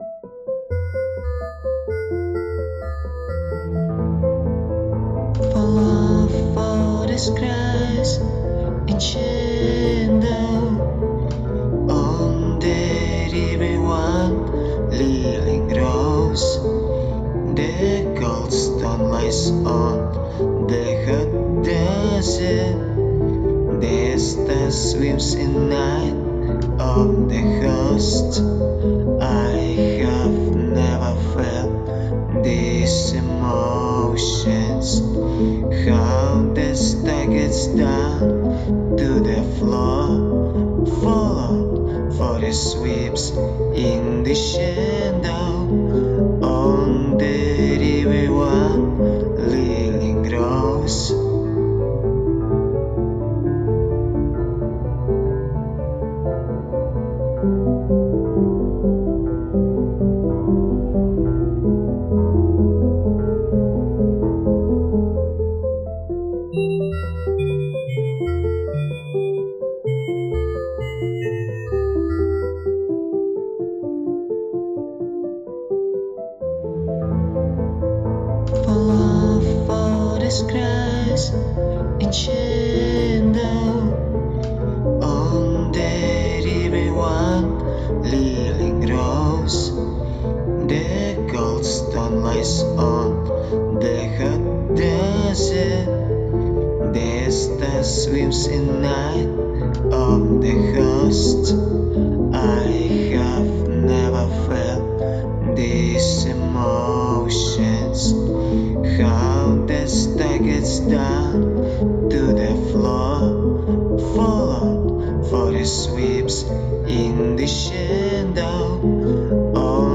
• Жанр: Рок
• Жанр: Классика